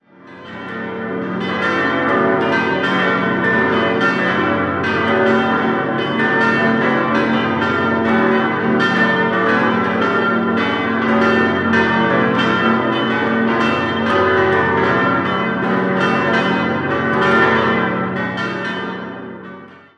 7-stimmiges Geläute: g°-b°-c'-d'-es'-f'-g'
Die Glocken wurden 1933 von der Gießerei Rüetschi in Aarau hergestellt.
Das siebenstimmige Geläut ist mit 17.556 kg das schwerste Glockengeläut im Kanton Zürich.